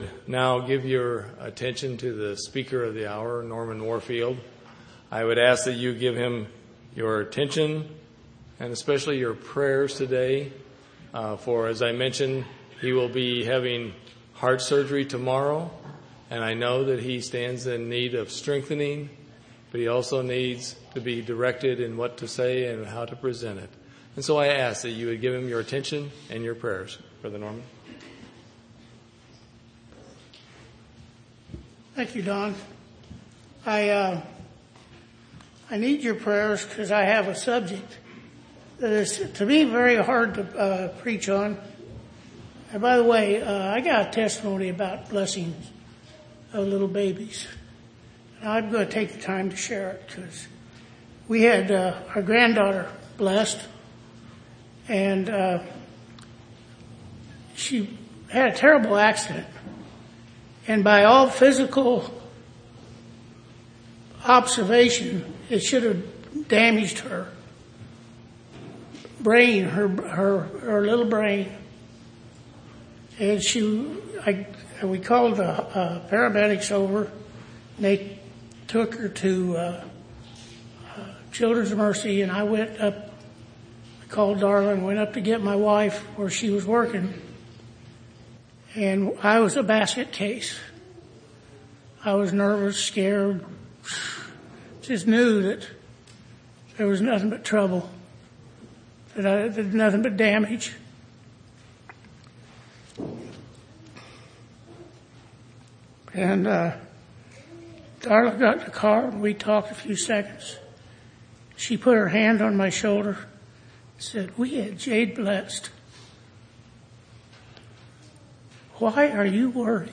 5/30/2010 Location: Temple Lot Local Event